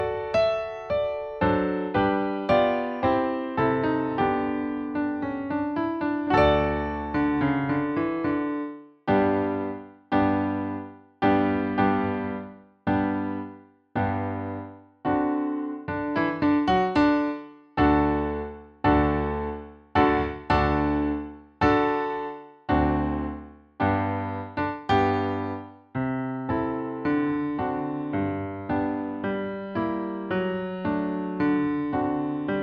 nagranie akompaniamentu pianina
I część: 110 bmp – wersja wirtuozowska
Nagranie nie zawiera rubat ani zwolnień.
Nagranie dokonane na pianinie cyfrowym, strój 440Hz
piano